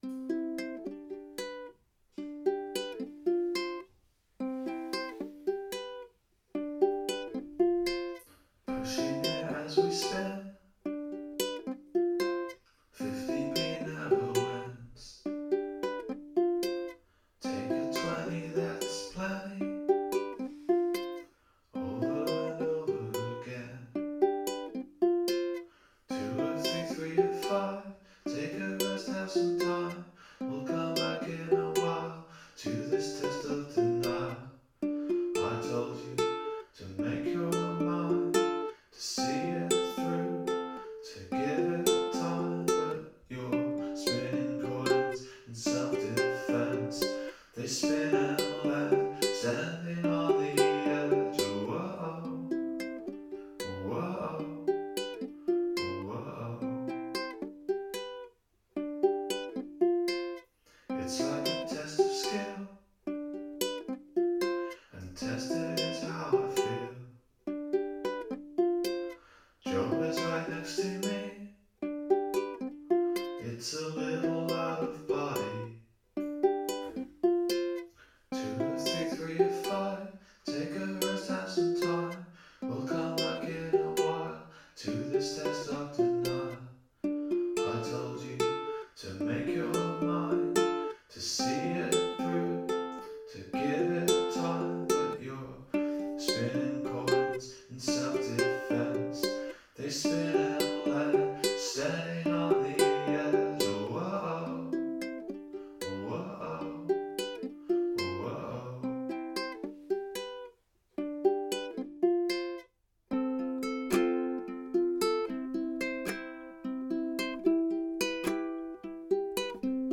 One is flanged, which is a good start, but not quite enough.